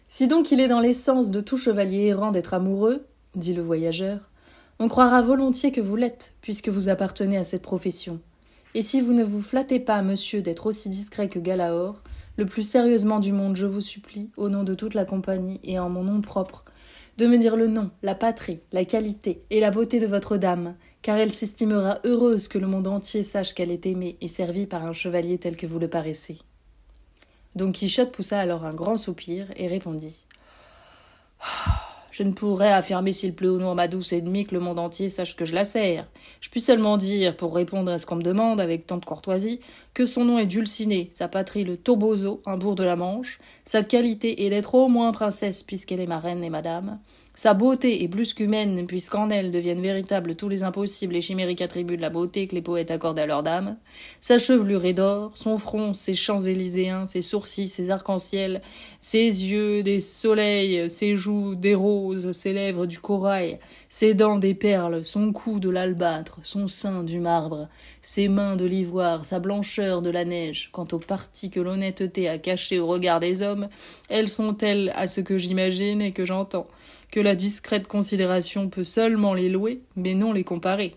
27 - 49 ans - Mezzo-soprano